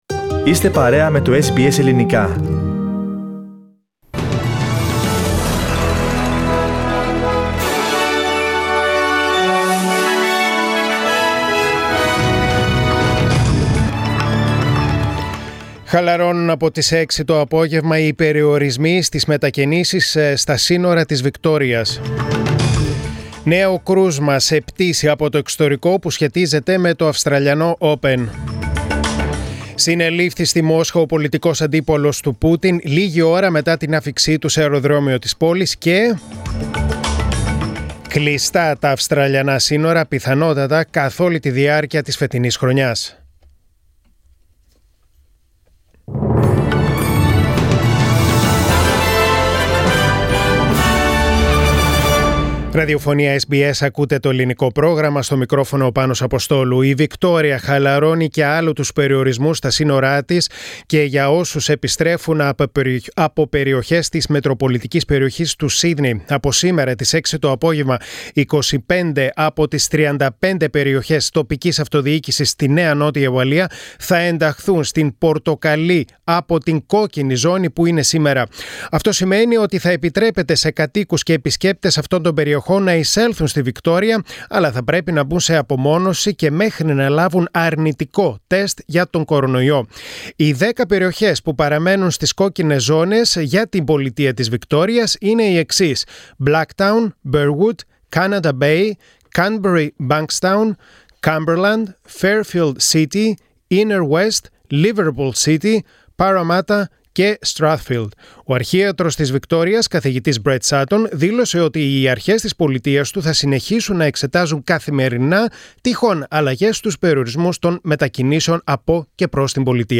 Press Play on the main photo and listen the News Bulletin (in Greek) Share